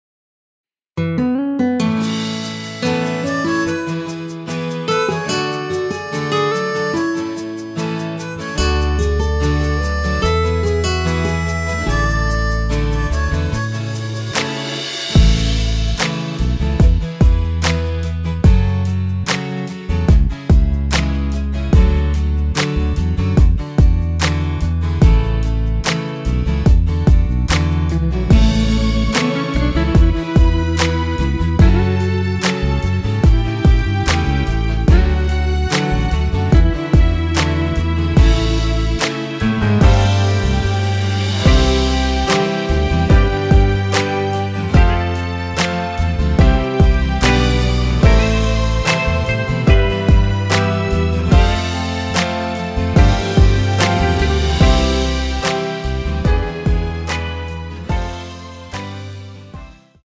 앨범 | O.S.T
앞부분30초, 뒷부분30초씩 편집해서 올려 드리고 있습니다.
중간에 음이 끈어지고 다시 나오는 이유는